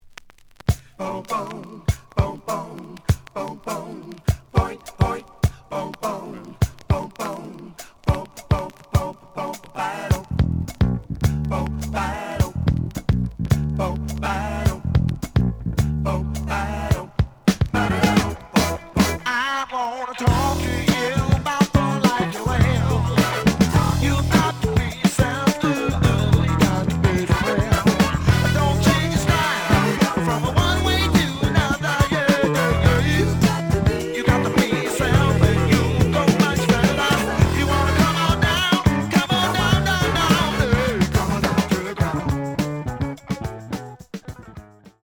The audio sample is recorded from the actual item.
●Genre: Funk, 70's Funk
B side plays good.)